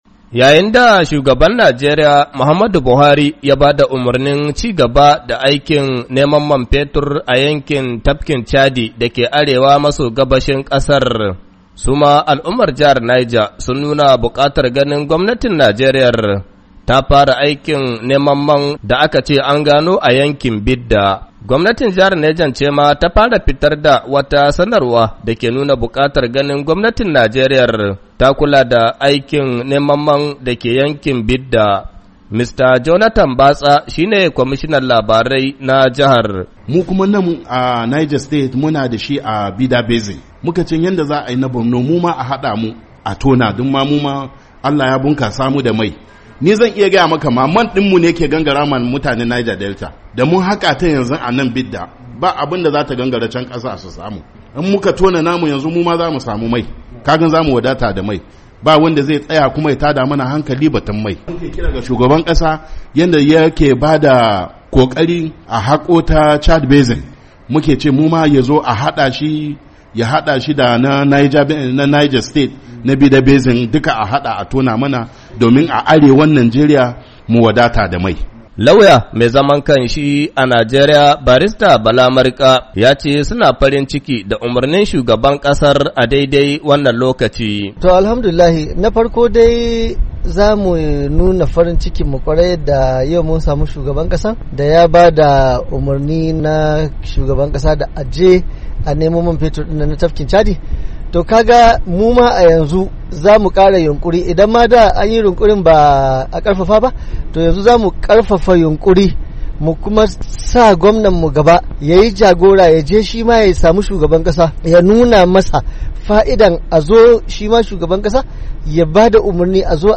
Saurari rahotan